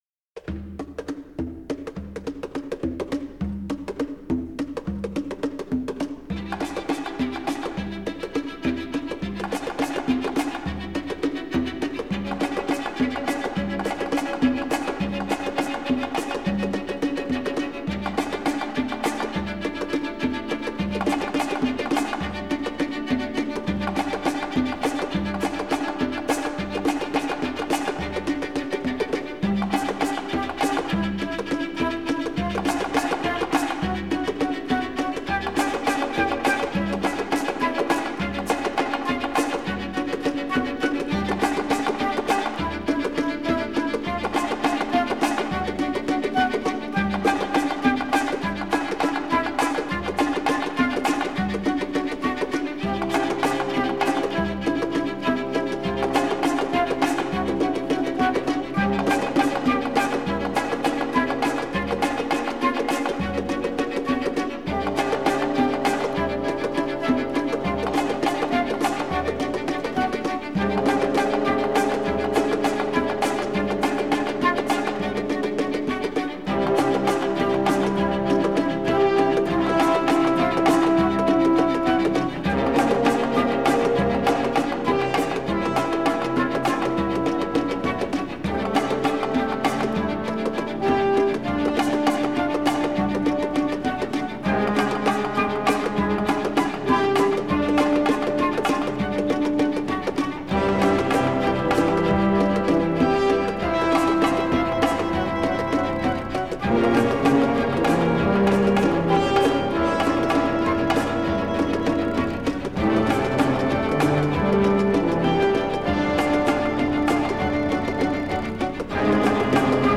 Genre : Classique / Jazz / Avant Garde